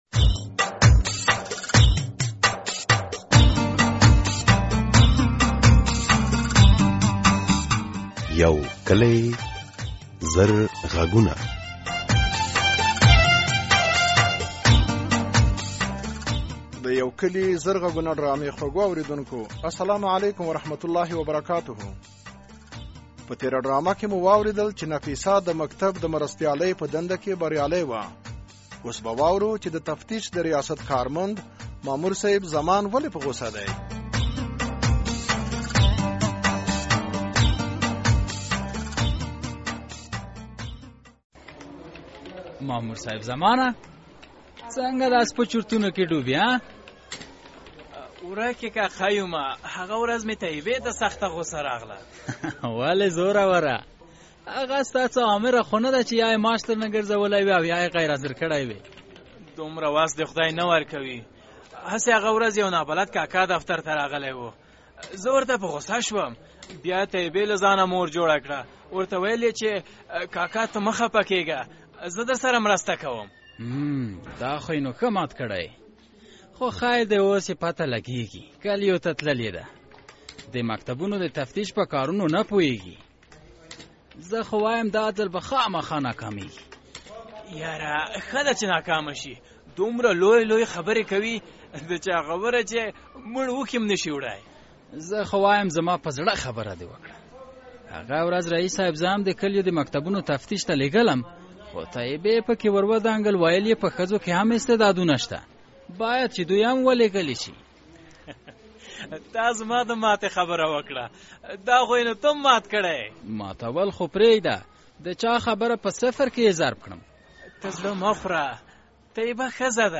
د یو کلي زرغږونو په اونیزه ډرامه کې د نورو موضوعاتو تر څنګ د پولیسې مرسل له کارونو او له هغې سره د کلیوالو...